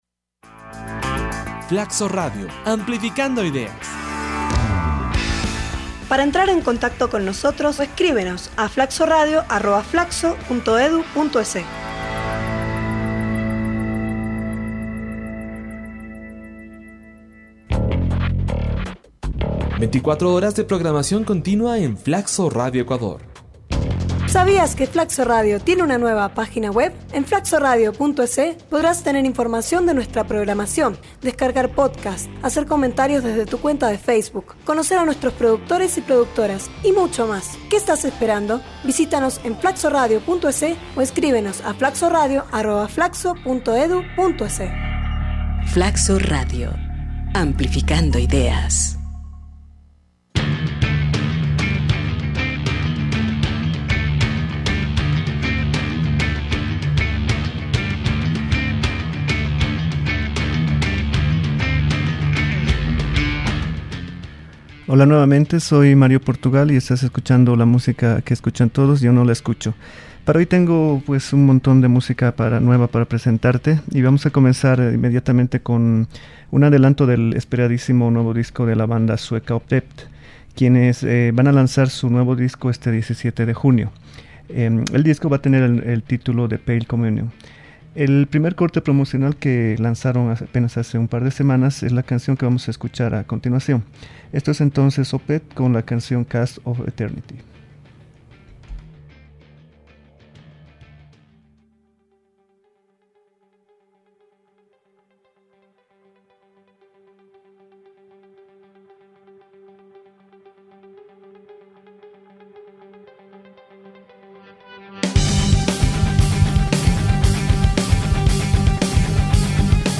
Recordamos a un clásico del metal industrial